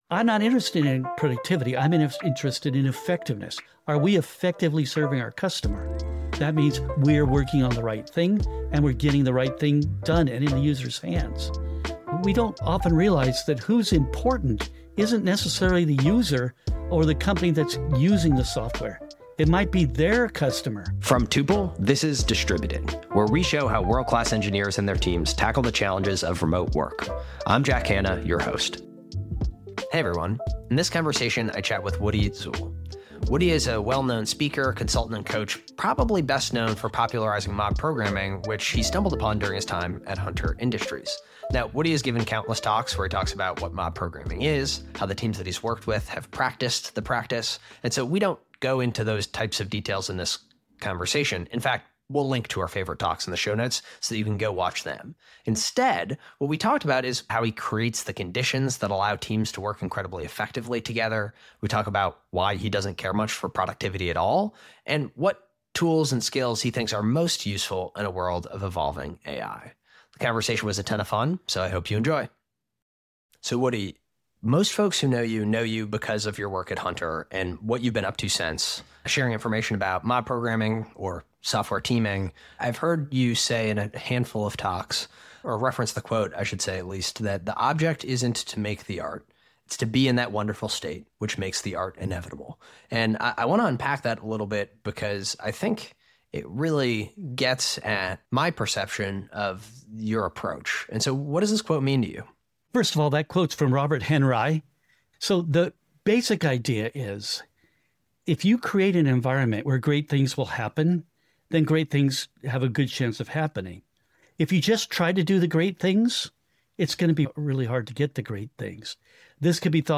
1 OpenAI cofounder Greg Brockman on the scaling hypothesis and refactoring as a killer AI use case 31:51 Play Pause 4d ago 31:51 Play Pause Play later Play later Lists Like Liked 31:51 Greg Brockman—OpenAI cofounder and Stripe's first engineer—joins John Collison to talk about research-driven product development, an early moment he thought OpenAI was doomed, S curves in AI advancement, and energy bottlenecks.